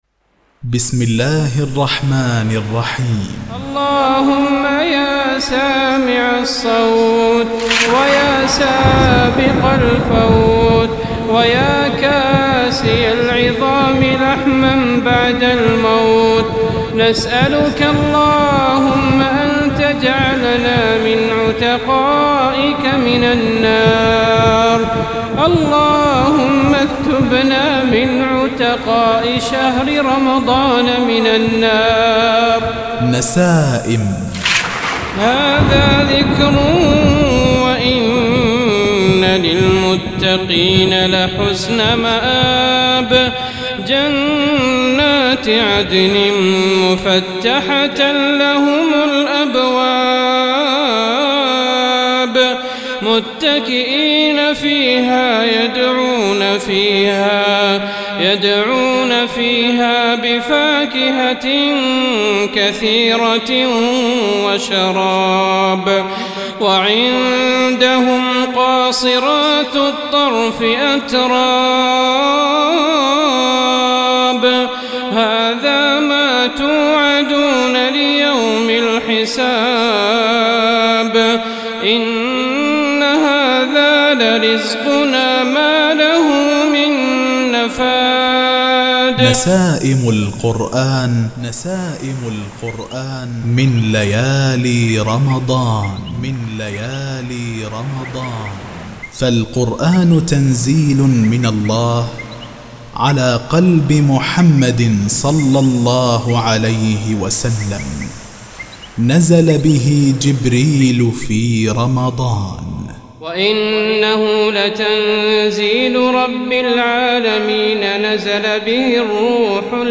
من جامعي الفاتح والسلام - بمملكة البحرين